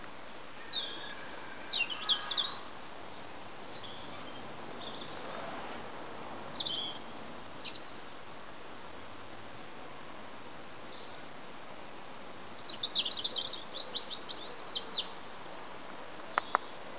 鸟叫声 " 罗宾
描述：一只知更鸟（欧洲）在唱歌。 用富士F810数码相机录制的。
Tag: 场记录 鸟的歌声 知更鸟